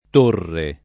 torre [